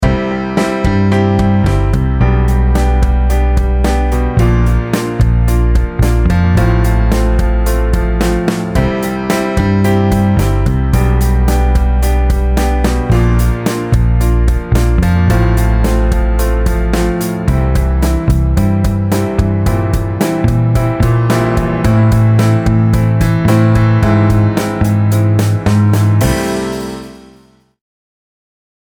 Here is an example of a drummer having good rhythmic accuracy:
Rhythmic-Accuracy-2-EX2-GOOD-Drummer.mp3